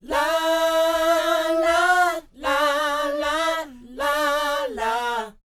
NA-NA A AU-L.wav